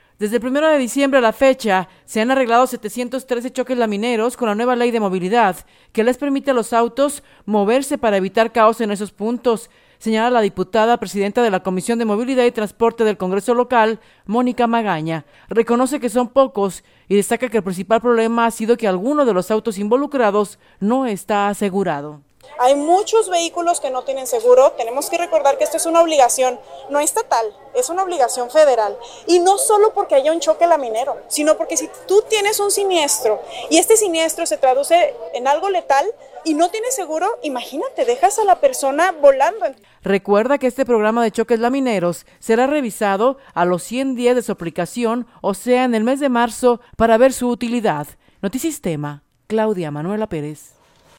Desde el primero de diciembre a la fecha se han arreglado 713 choques lamineros con la nueva Ley de Movilidad, que les permite a los autos moverse para evitar caos en esos puntos, señala la diputada presidenta de la Comisión de Movilidad y Transporte del Congreso local, Mónica Magaña. Reconoce que son pocos y destaca que el principal problema ha sido que alguno de los autos involucrados no está asegurado.